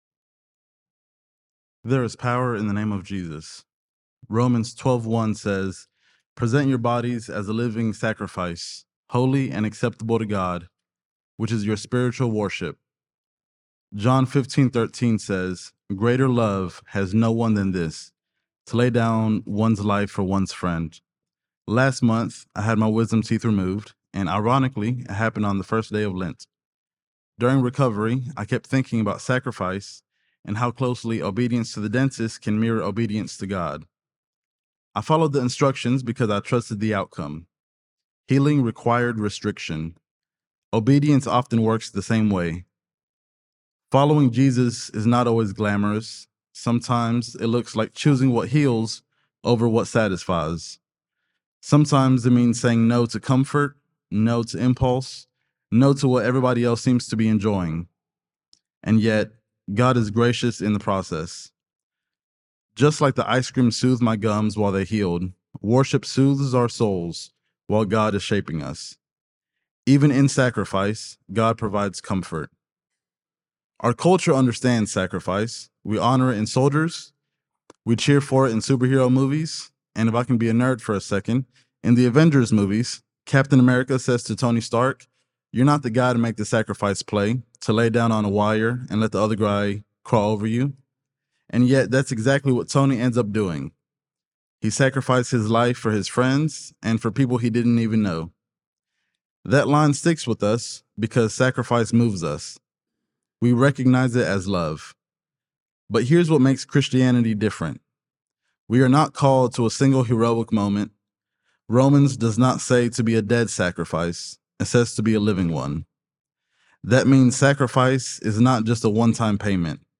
Good Friday Reflection
Faith Lutheran Church Knoxville
Sermon Podcast